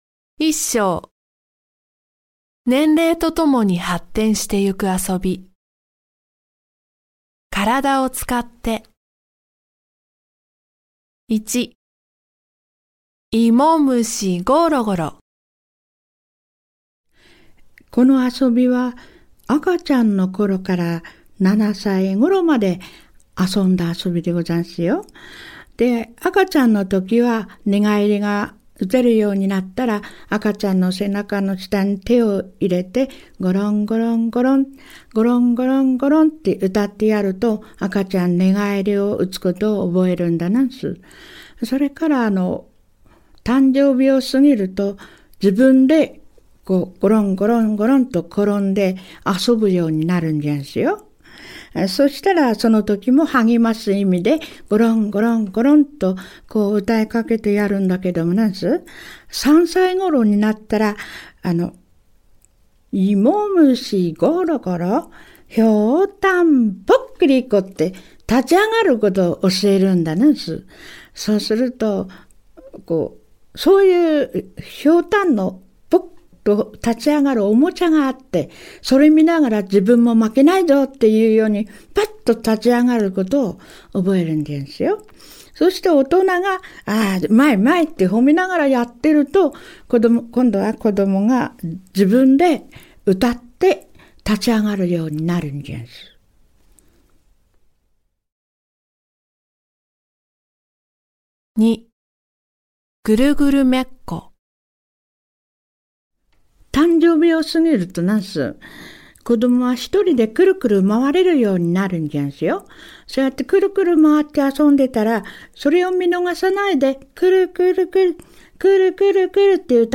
【６章 はやし唄】
【７章 子守歌】
warabeuta_ouyou.mp3